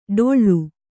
♪ ḍoḷḷu